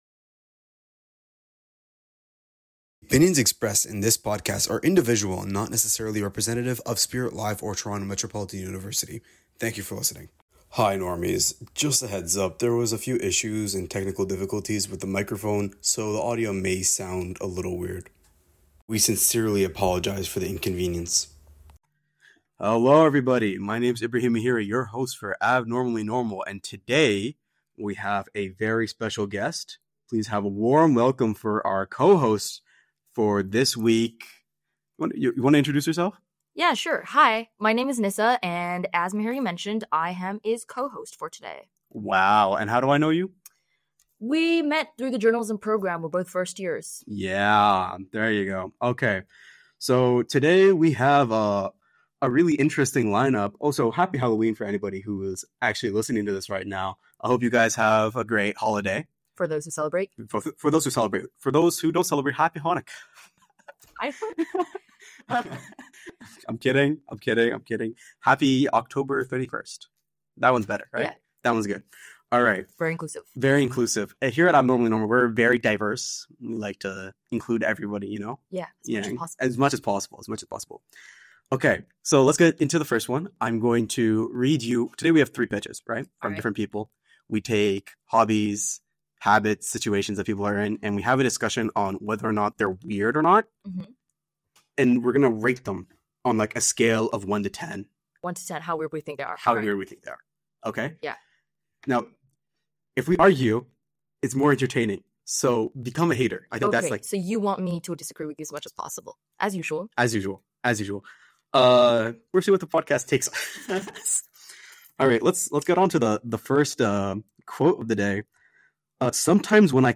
Each episode blends candid conversation with lighthearted debate, turning habits, hobbies, and odd routines into stories about what “normal” truly means. It’s funny, honest, and a little peculiar, a place where the unusual is usual, the serious is unserious, and most of all, where the abnormal is normal.